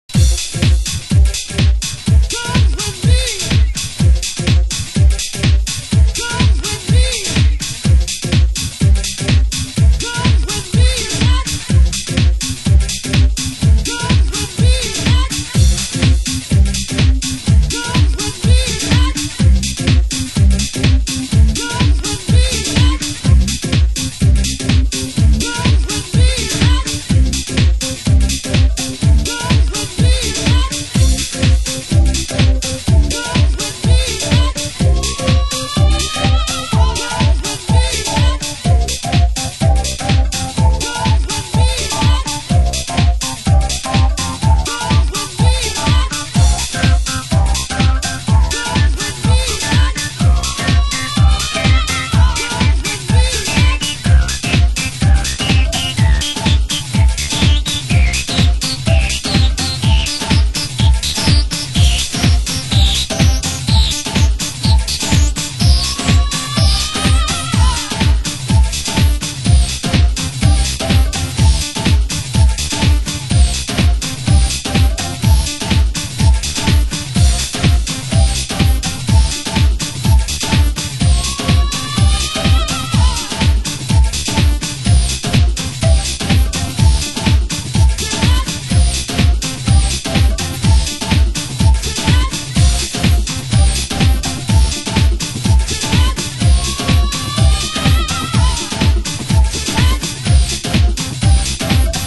盤質：少しチリパチノイズ有　　ジャケ：スレ有/底部大きく裂け